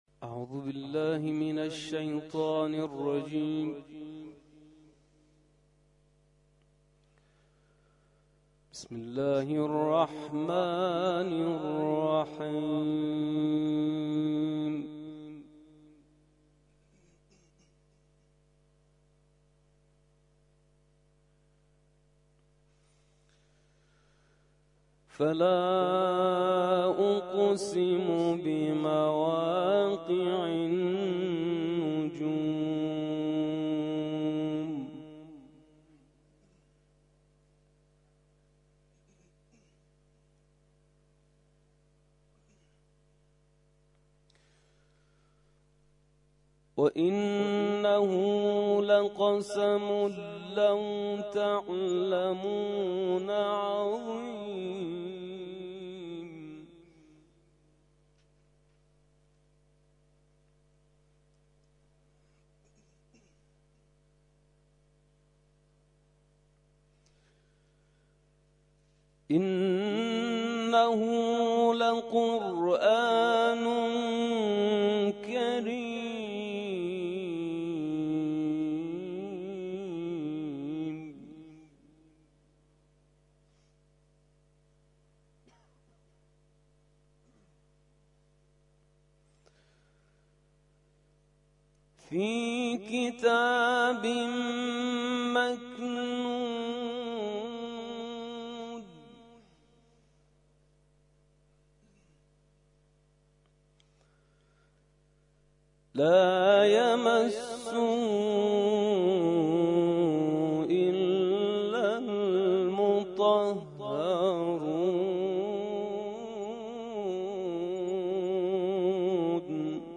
تلاوت ظهر - سوره واقعه آیات (۷۵ الی ۹۱)